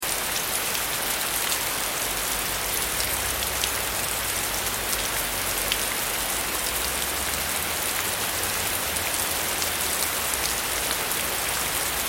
دانلود صدای طوفان 19 از ساعد نیوز با لینک مستقیم و کیفیت بالا
جلوه های صوتی
برچسب: دانلود آهنگ های افکت صوتی طبیعت و محیط دانلود آلبوم صدای طوفان از افکت صوتی طبیعت و محیط